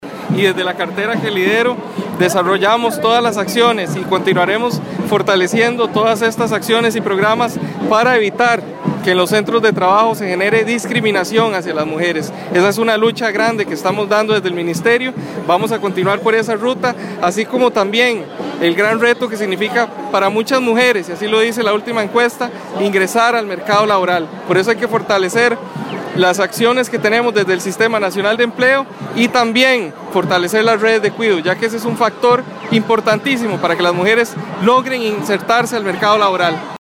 El ministro de trabajo Steven Núñez fue uno de los jerarcas que participó en esta manifestación que culminaba en la Plaza de la Democracia, él resaltó la importancia de lograr que las mujeres tengan un rol de igualdad en el ámbito laboral.